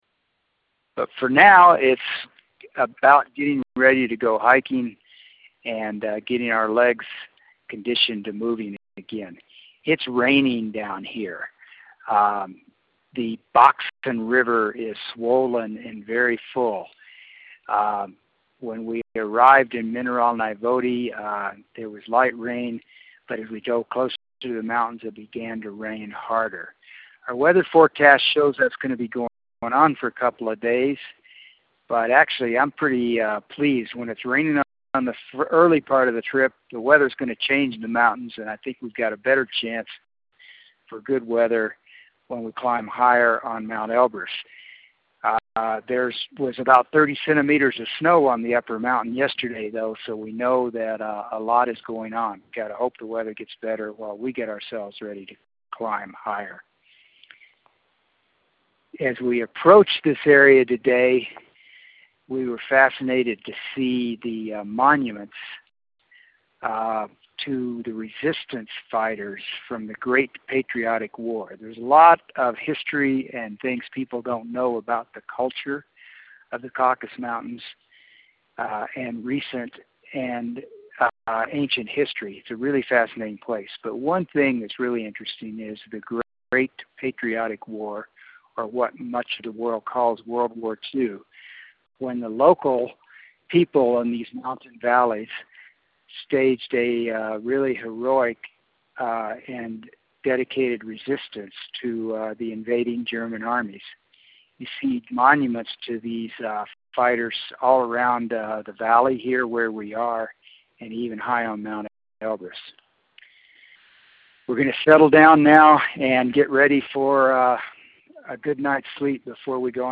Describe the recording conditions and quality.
We are in the mountains now, calling you from our beautiful lodge in the Caucasus Mountains.